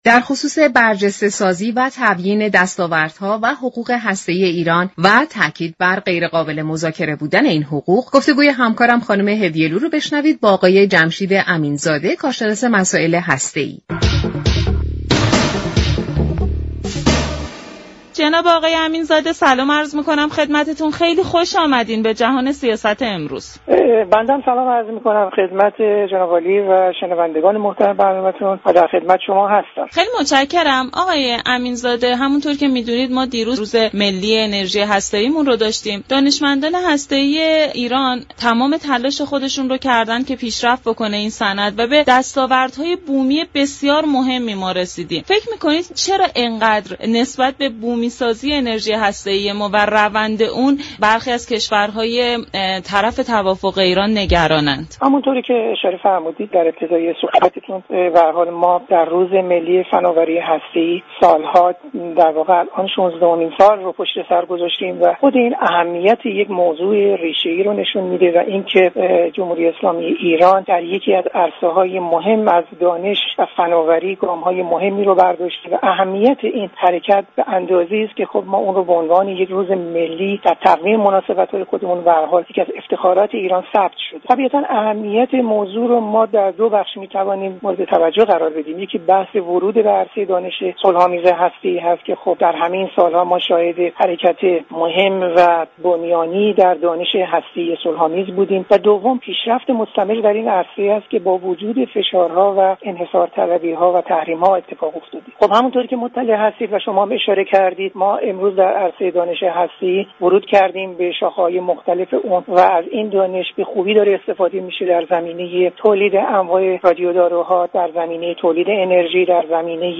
برنامه «جهان سیاست» شنبه تا چهارشنبه هر هفته ساعت 15:30 از رادیو ایران پخش می شود.